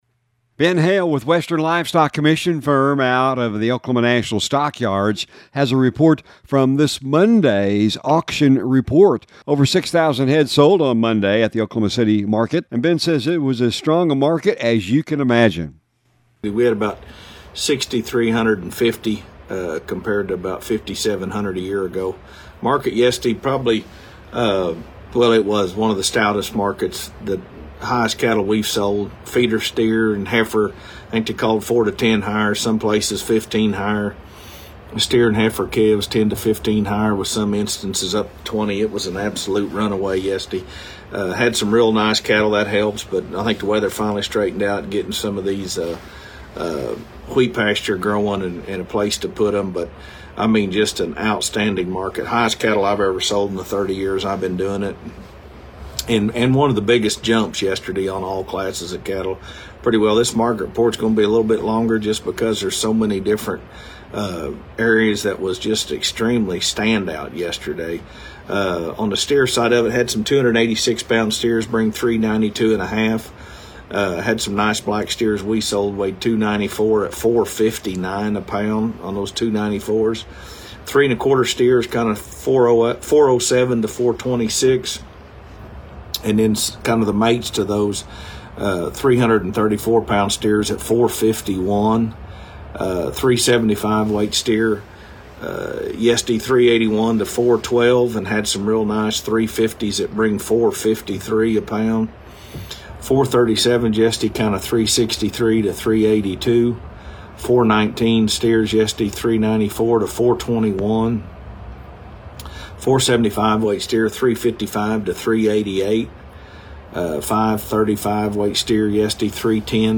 On Tuesdays, we get the latest market commentary